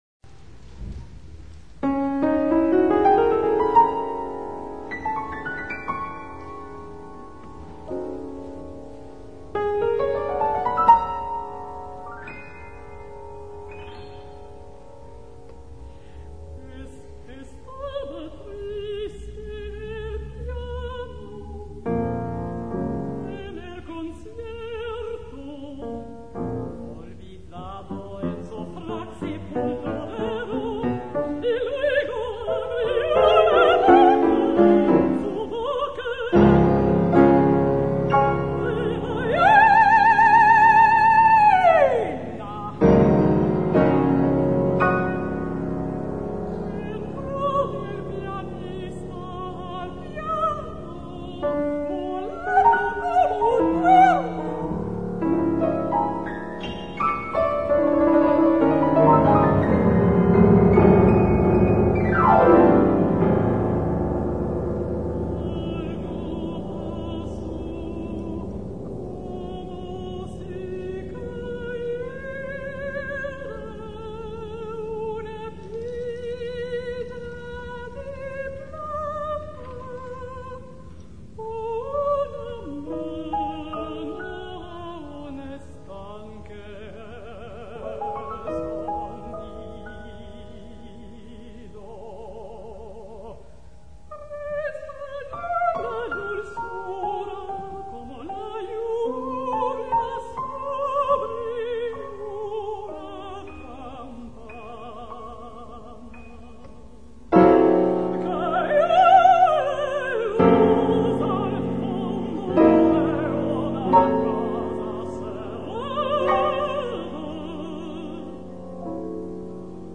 Mezzo-soprano and piano
was witty, stylish and dramatic